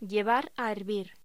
Locución: Llevar a hervir